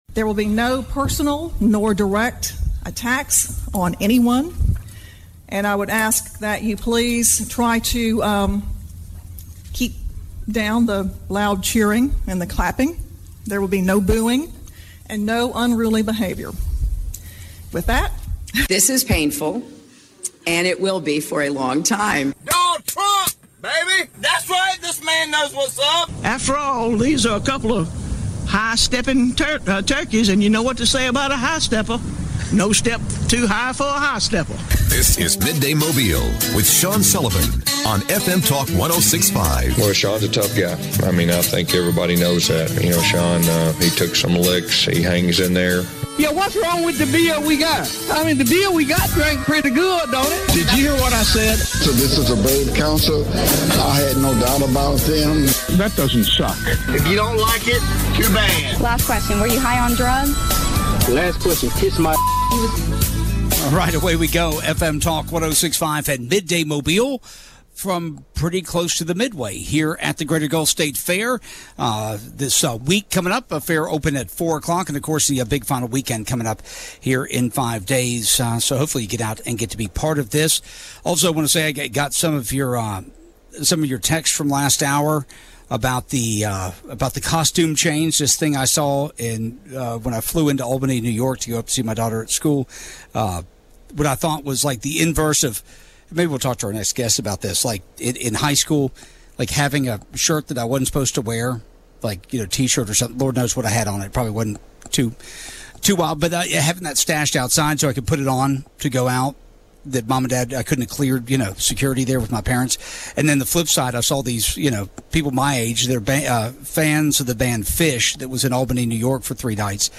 Live from The Grounds for the Greater Gulf State Fair